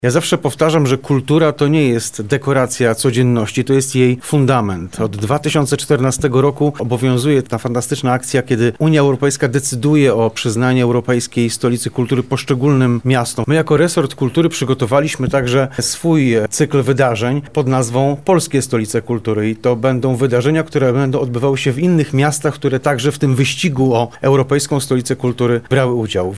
To rok wypełniony wydarzeniami kulturalnymi i Lublin na pewno na to zasługuje – mówi Maciej Wróbel, MKiDN.